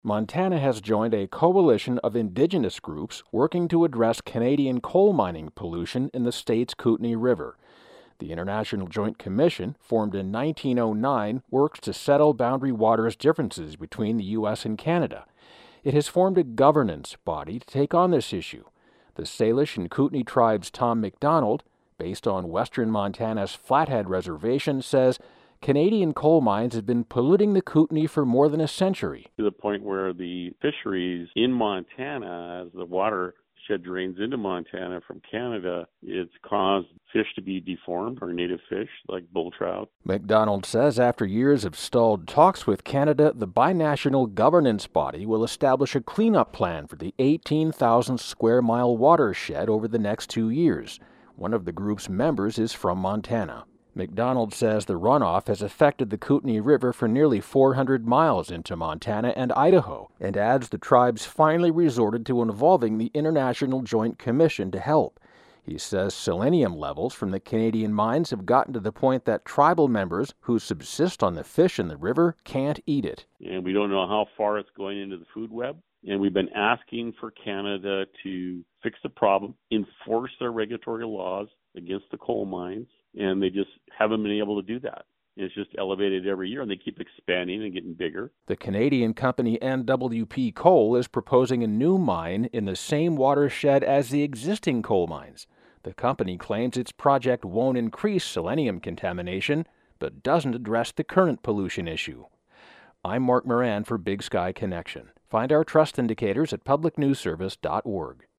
Comments from Tom McDonald, vice chair, Salish (SALE-ish) and Kootenai (KOOT-nee) Tribal Council.